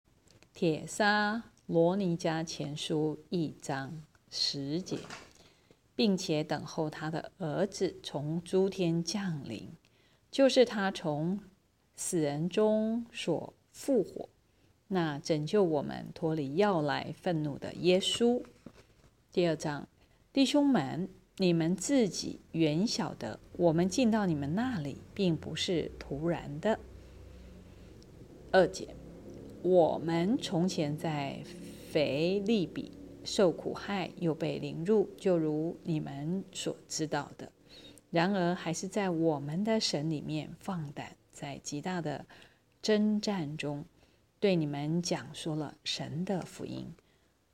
朗讀